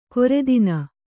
♪ koredina